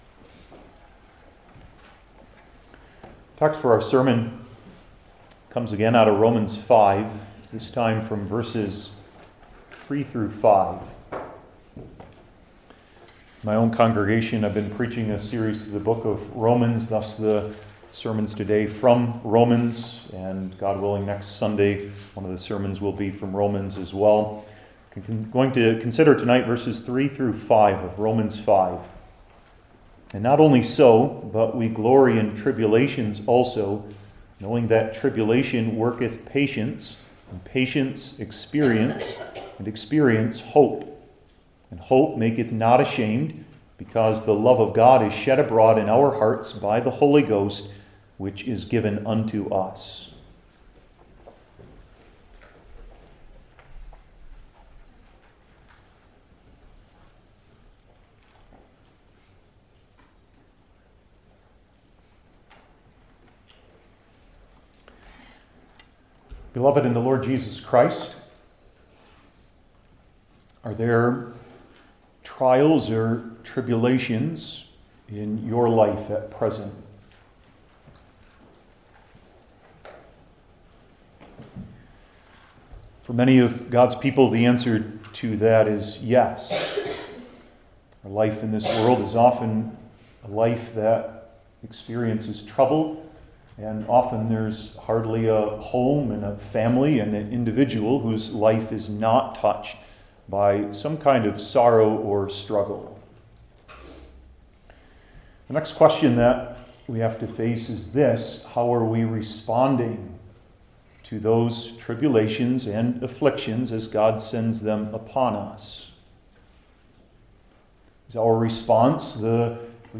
Romans 5:3-5 Service Type: New Testament Individual Sermons I. The Confession II.